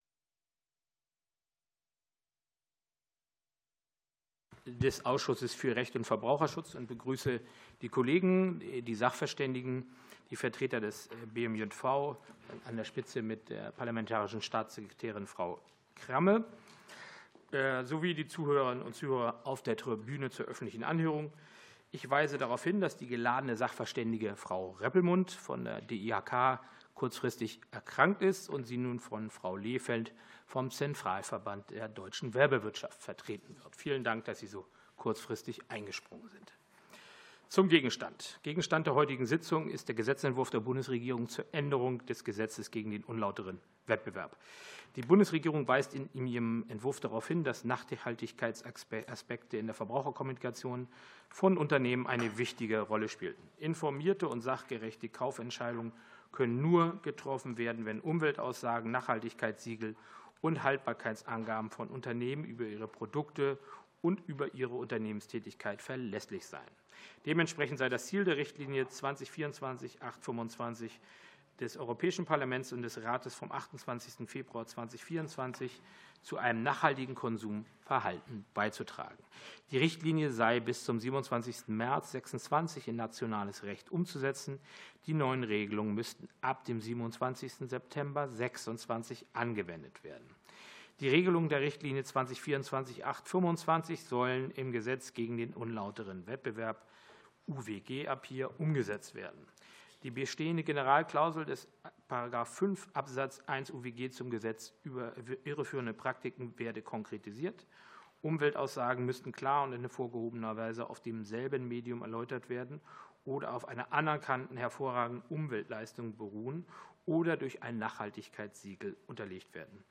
Öffentliche Anhörung des Rechtsausschusses